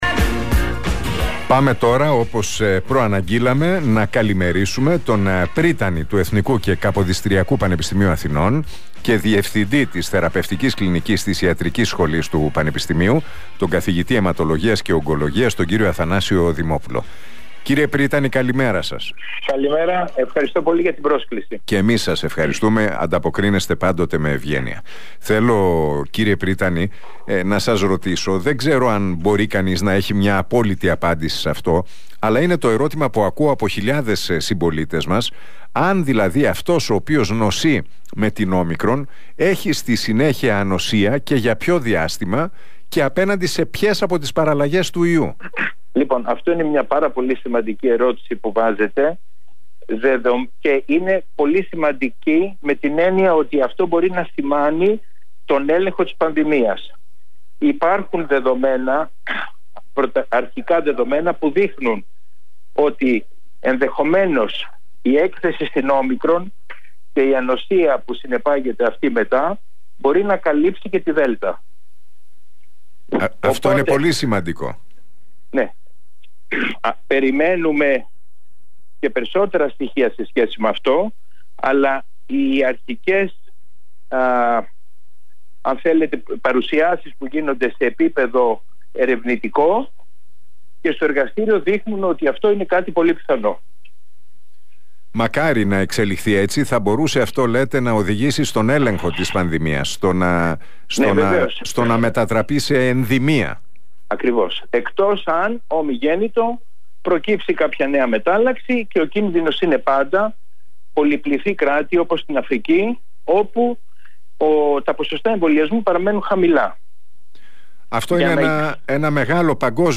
Ο Αθανάσιος Δημόπουλος μιλώντας στον Realfm 97,8 και στην εκπομπή του Νίκου Χατζηνικολάου, δήλωσε ότι “υπάρχουν δεδομένα που δείχνουν ότι ενδεχομένως η έκθεση στην Όμικρον, και η ανοσία που συνεπάγεται μετά, μπορεί να καλύψει και την Δέλτα”.